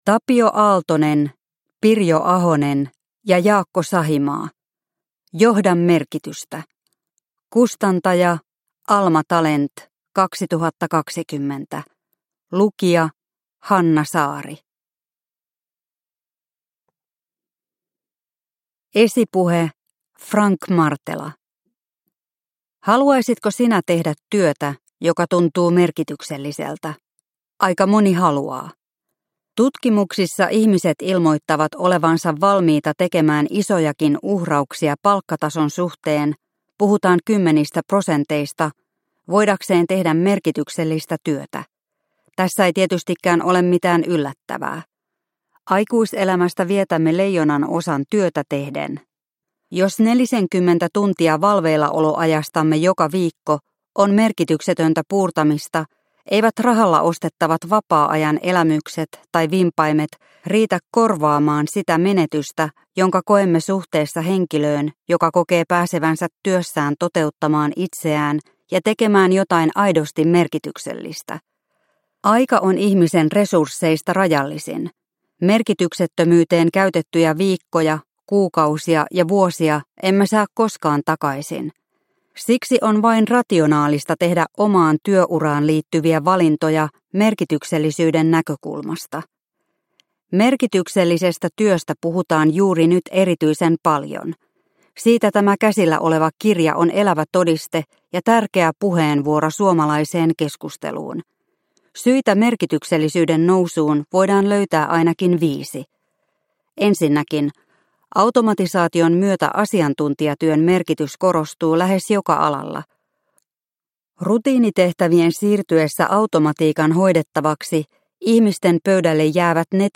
Johda merkitystä – Ljudbok – Laddas ner